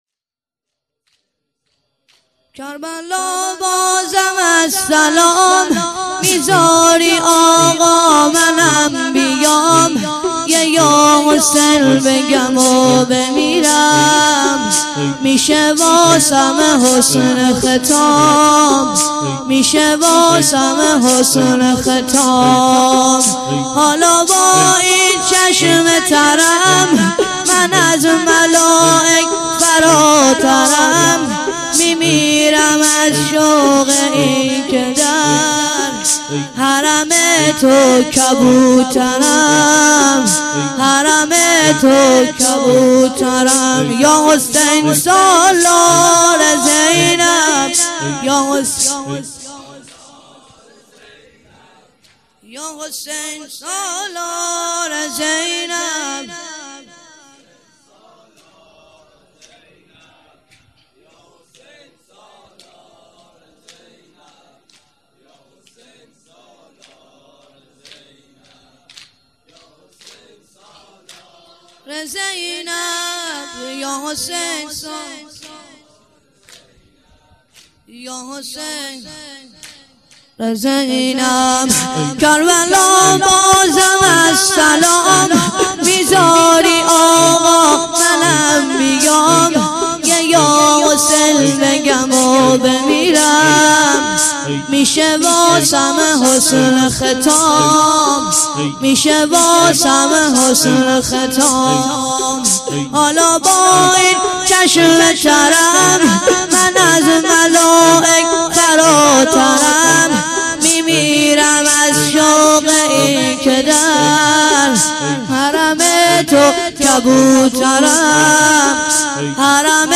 تک - کربلا بازم از سلام - مداح